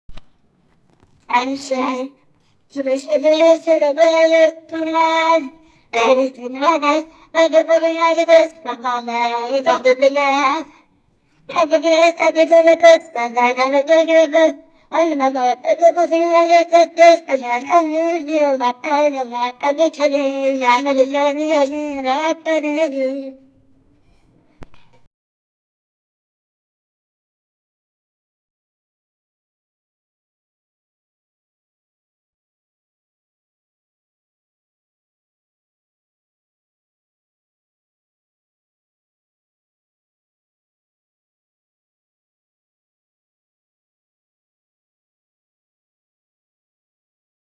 rafale-ak47-iu7artos.wav